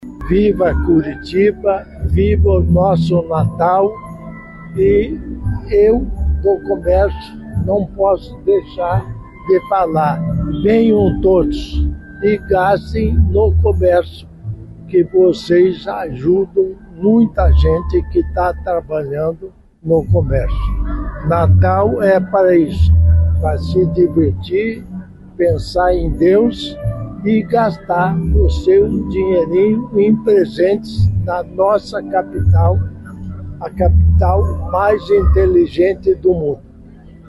O vice-governador do Paraná, Darci Piana (PSD), pediu para que a população movimente o comércio local.